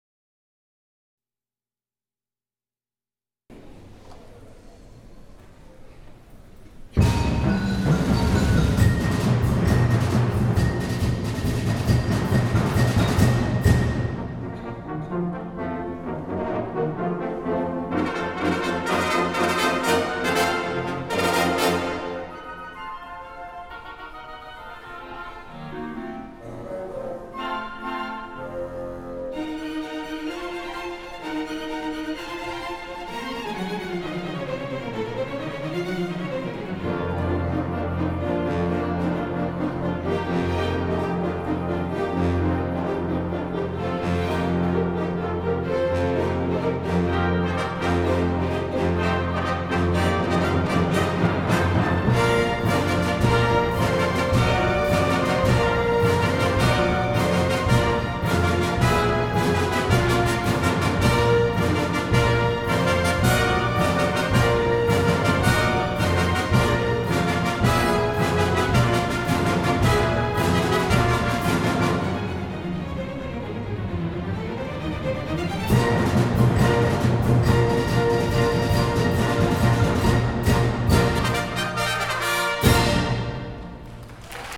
Ensemble: Full Orchestra